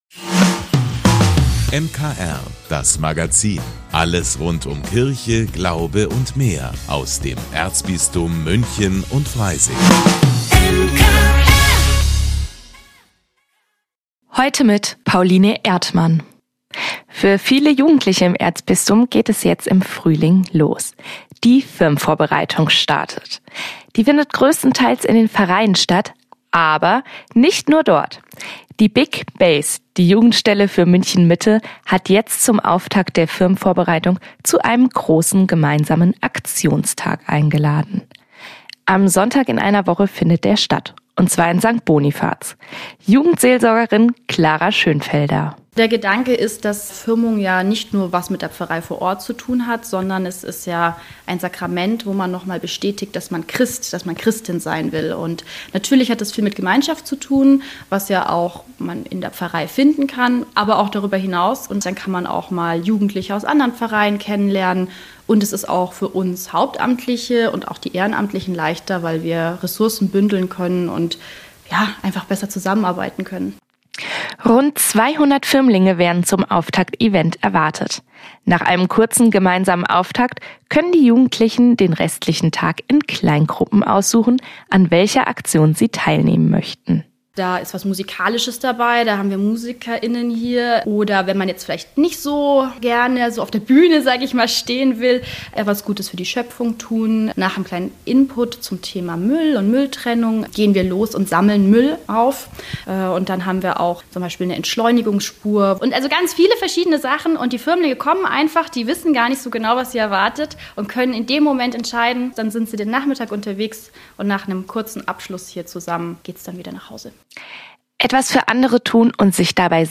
Außerdem können Sie im MKR-Magazin schon einmal in die neue Folge des Kitaradios hineinhören.